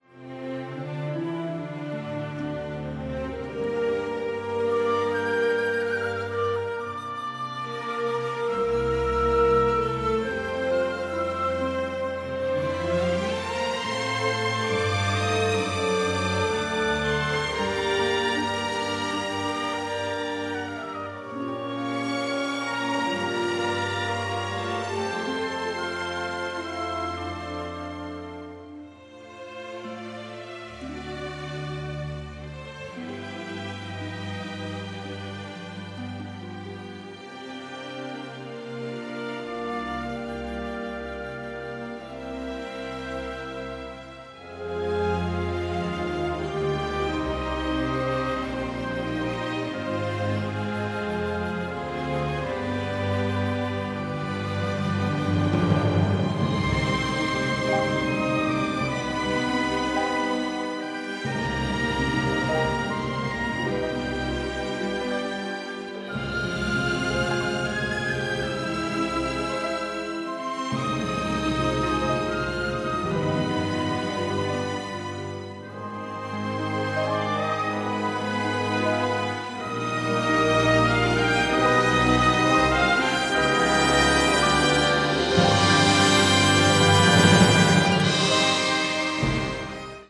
original motion picture score
the score is operatic in its power